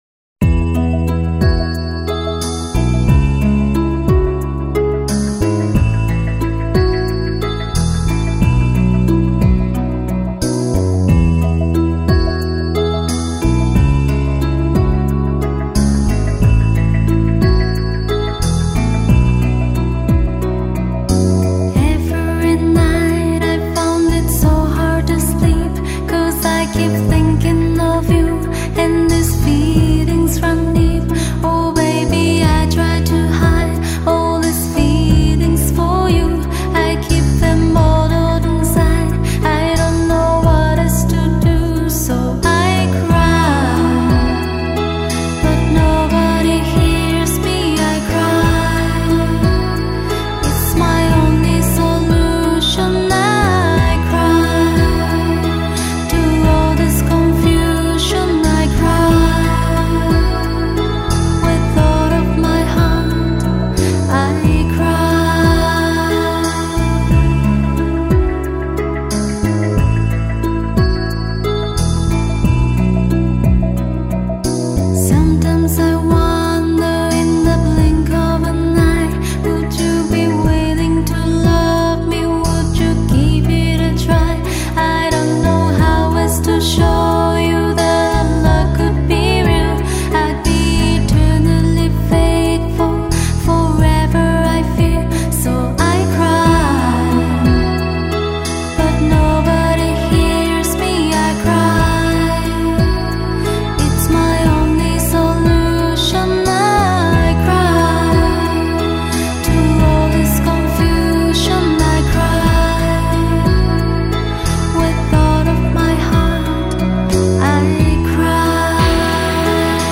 柔美的女声英文歌